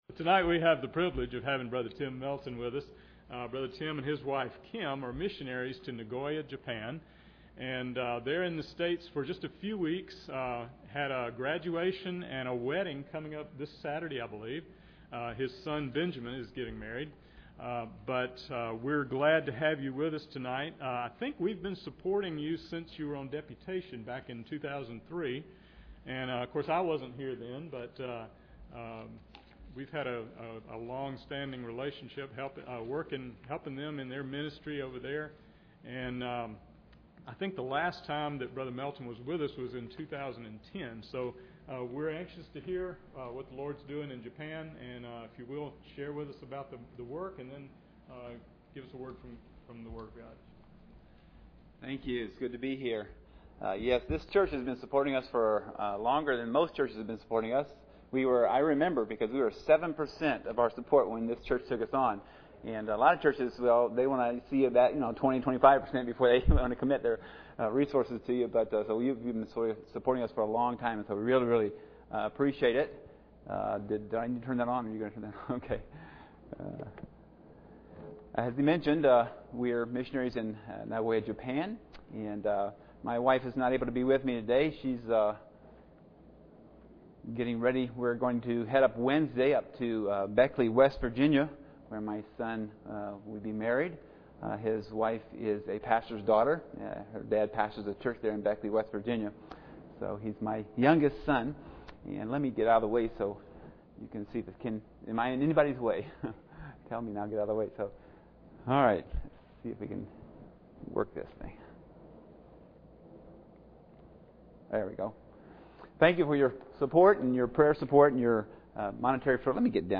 Passage: 1 Timothy 6:11-12 Service Type: Sunday Evening